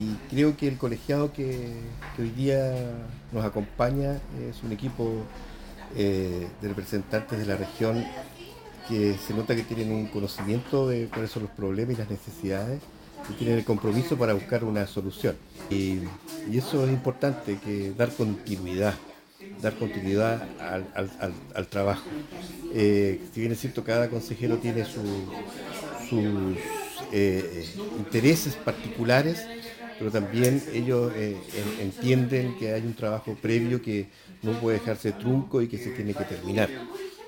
Por su parte, el consejero por Elqui que inicia su segundo periodo, Pedro Valencia, habló sobre lo que observa en este nuevo grupo de trabajo.